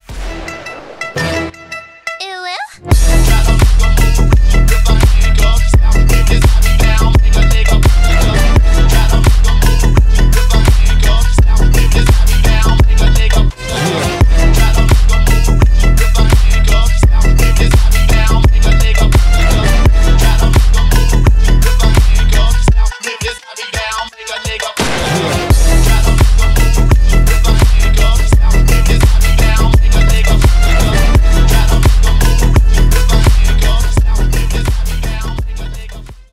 Рэп и Хип Хоп
восточные